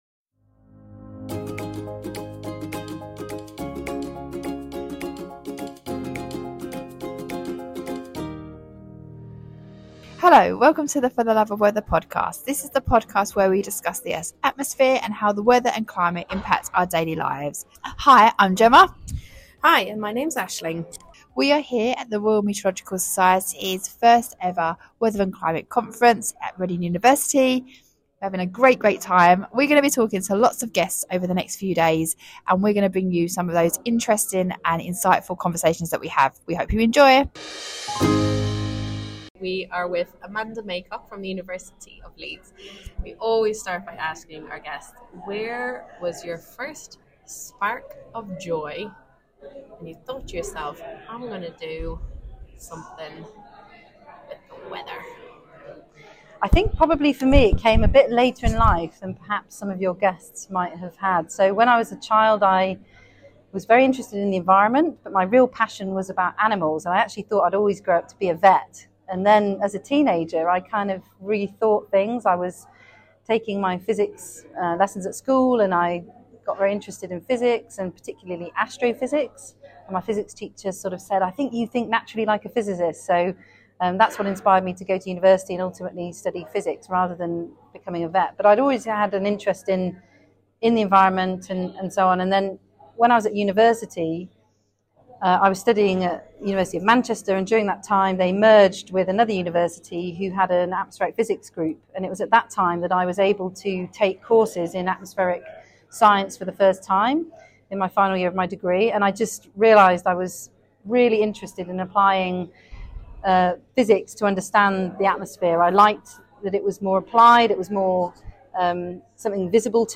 At the start of July 2024 we took the podcast to the Royal Meteorological Society’s first ever weather and climate conference.
While there we also got a chance to talk to three brilliant scientists in a series of mini podcasts, which we have put together in this special episode.